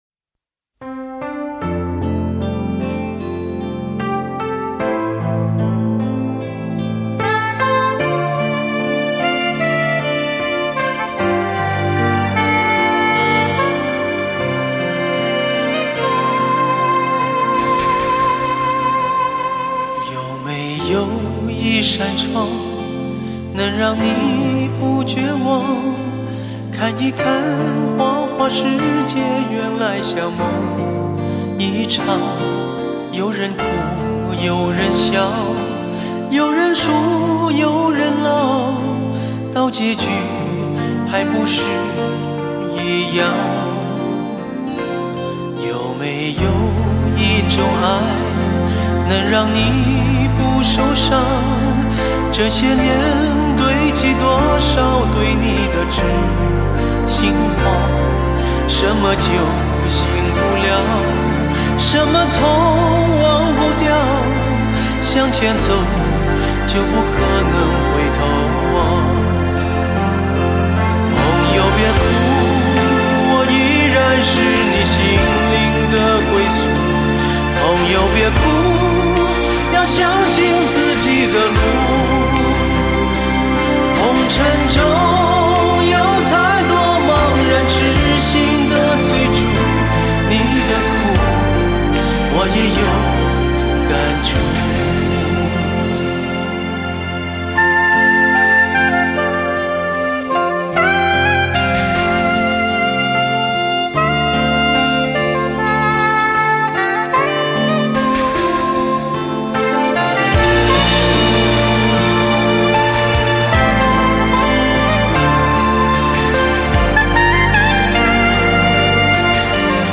乐坛巨星经典成名曲
无损音质原人原唱，经典！值得聆听永久珍藏